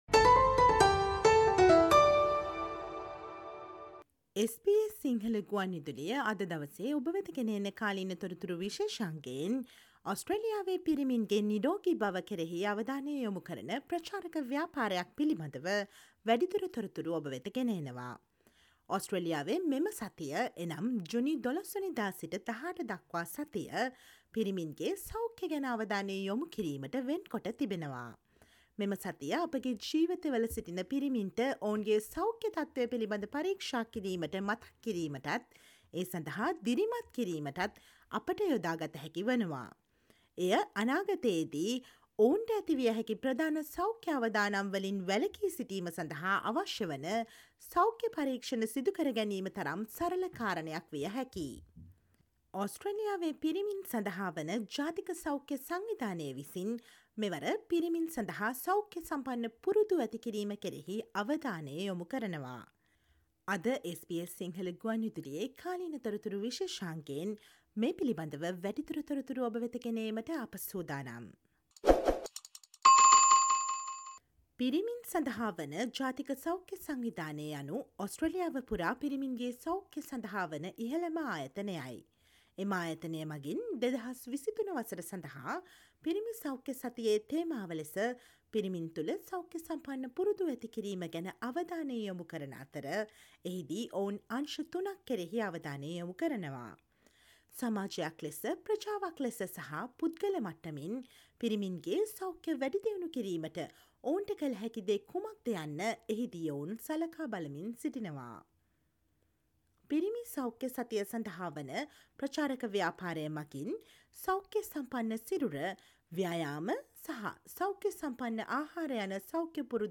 Listen to the SBS Sinhala radio current affair feature on Men's health week themed 'healthy habits' in 2023 in Australia